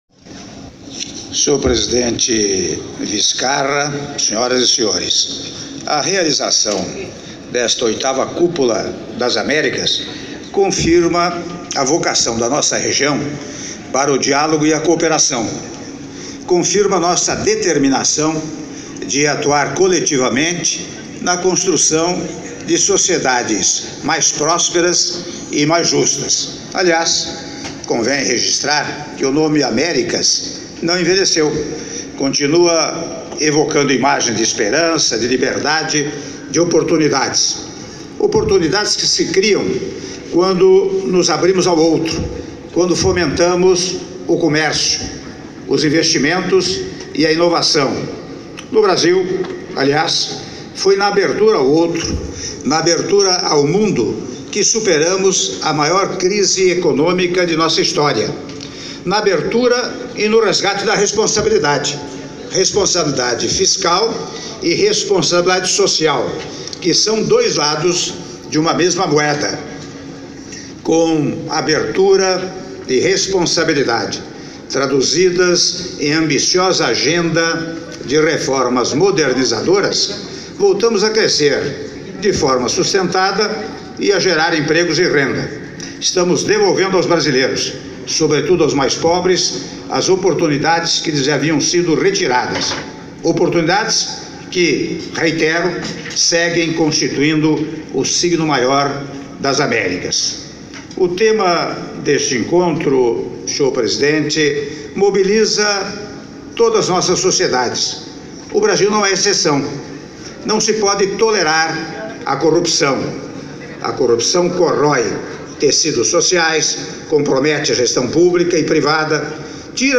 Áudio do discurso do Presidente da República, Michel Temer, durante Sessão Plenária da VIII Cúpula das Américas - Lima/Peru - (08min21s)